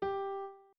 01_院长房间_钢琴_08.wav